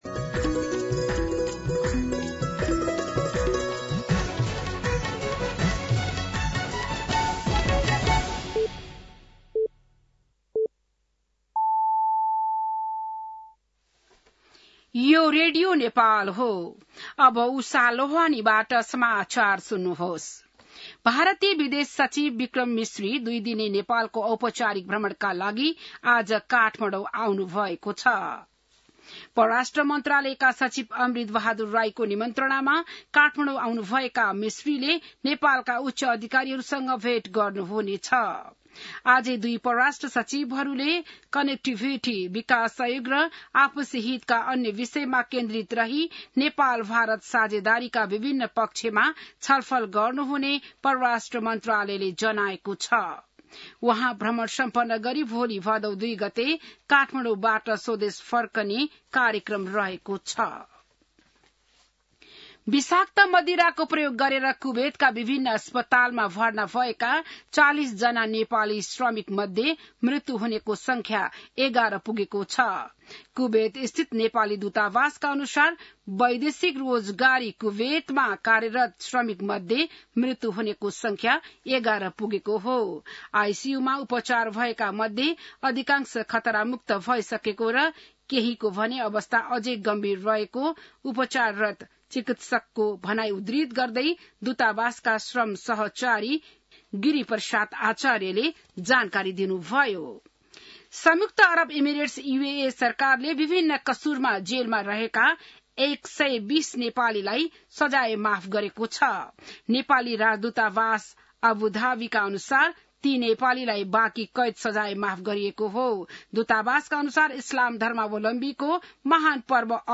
बिहान ११ बजेको नेपाली समाचार : १ भदौ , २०८२
11am-News-05-1.mp3